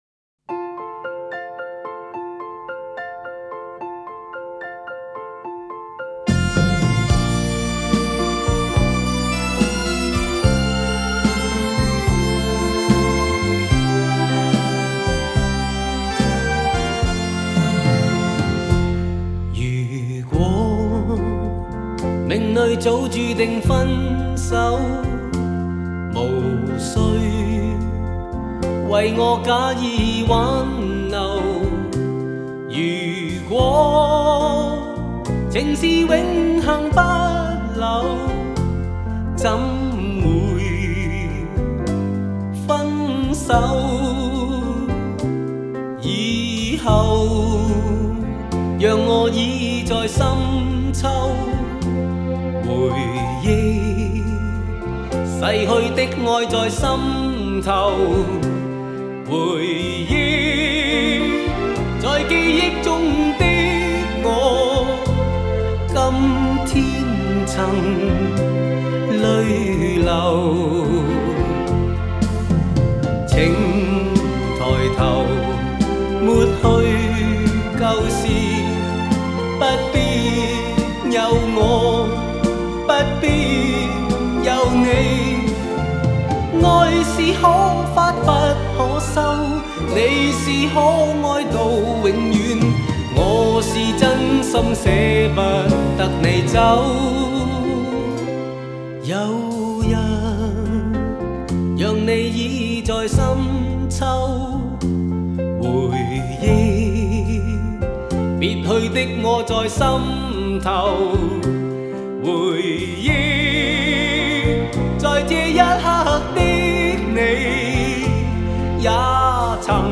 这首歌的旋律沉静深刻，歌词带着一种忧伤的美，仿佛是在诉说秋日渐凉时的爱情故事。